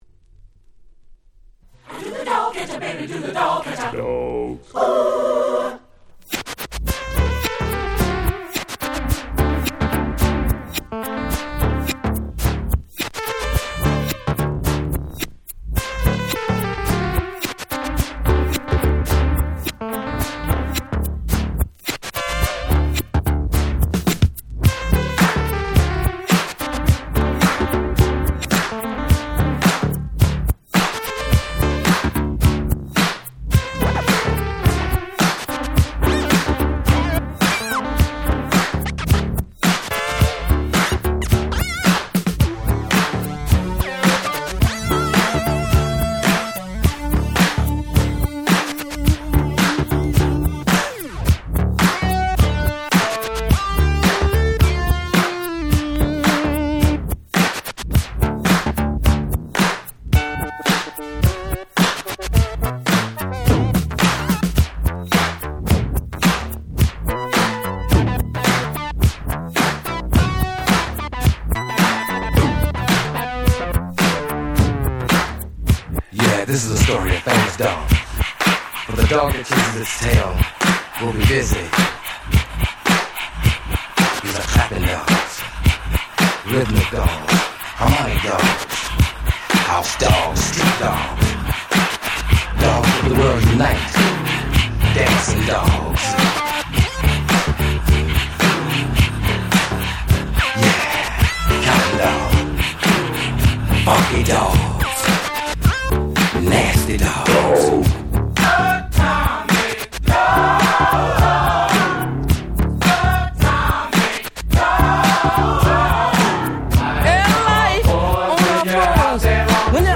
82' Super Hit Funk !!
ブリブリでついつい腰が動いてしまいます！()
P-Funk ピーファンク ダンスクラシックス ディスコ Disco